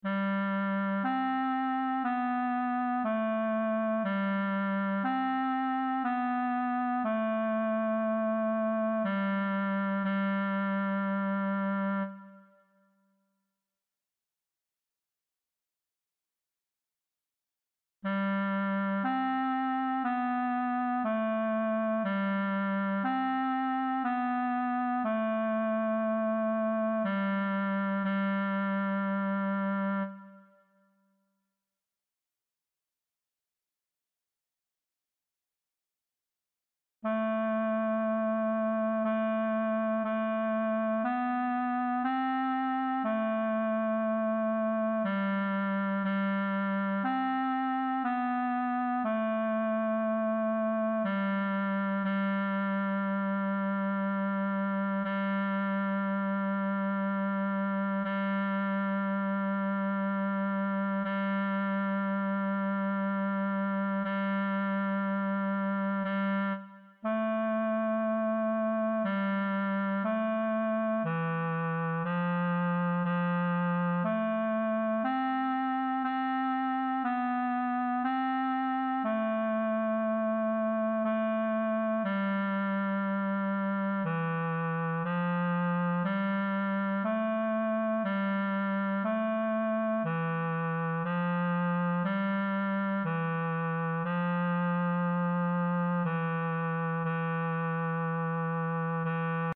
Voci (mp3): sopran, alto, tenor, bas, cor mixt